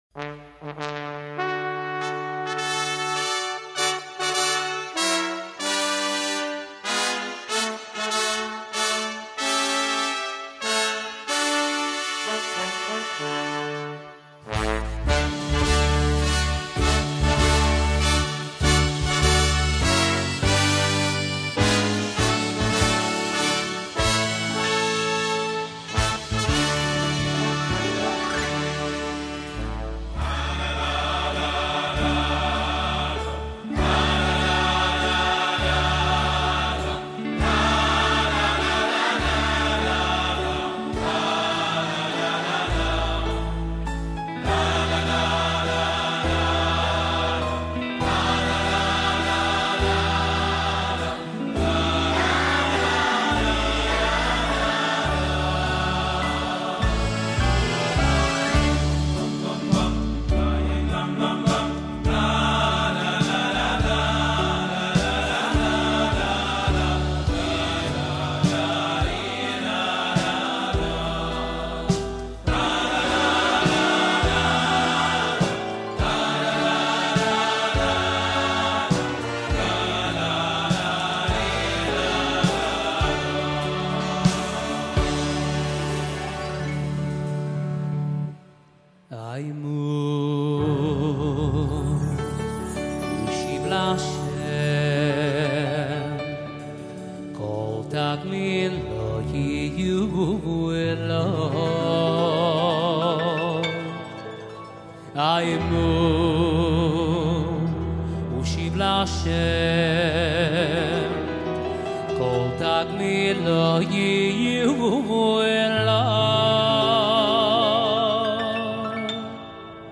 מסיום הש"ס